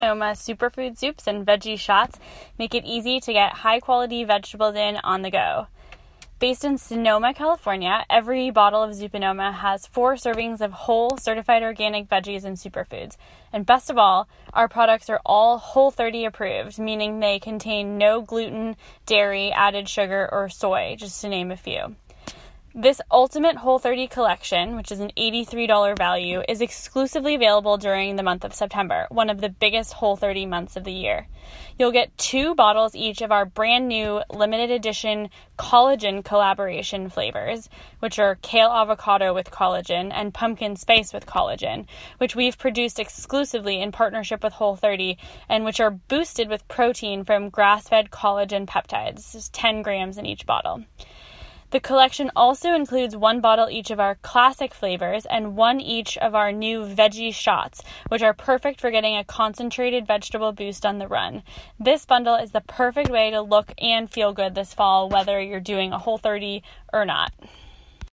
Vendor Voicemail play pause ZUPA NOMA introduces their Whole30 Collection, put together exclusively for the month of September.